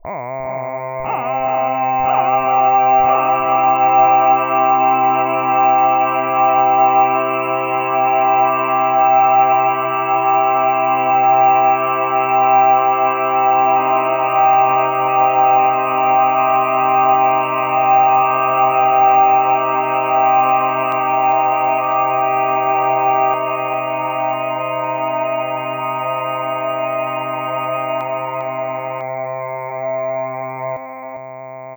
syntetiserade körklang
Alltså 2 dB limitering av de sporadiska topparna, detta innebär att den bearbetade ljudfilen har 2 dB lägre peak-nivå men jag har avsiktligt inte höjt upp medelnivån, detta för att underlätta en lyssningsjämförelse mellan original och limiterad version.
korsyntes_lim2dB.wav